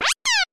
Sound effect from Super Mario RPG: Legend of the Seven Stars
Self-recorded using the debug menu
SMRPG_SFX_Baby_Yoshi.mp3